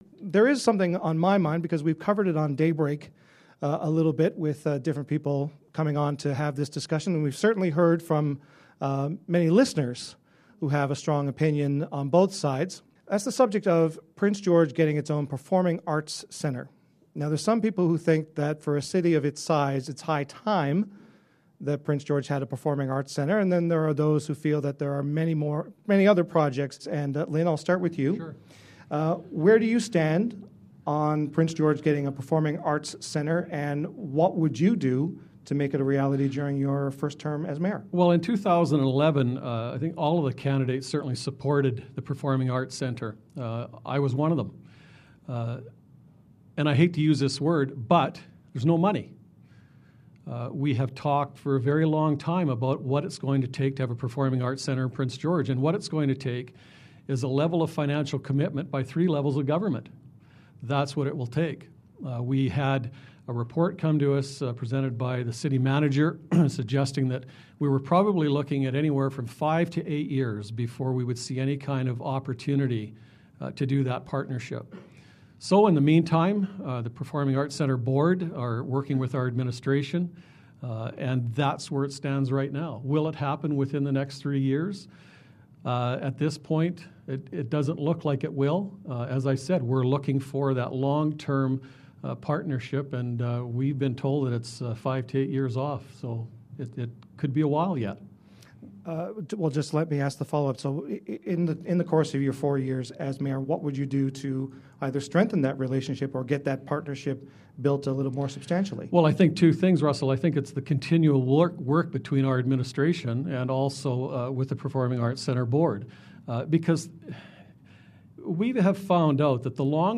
Mayoral candidates Don Zurowski and Lyn Hall answer the question.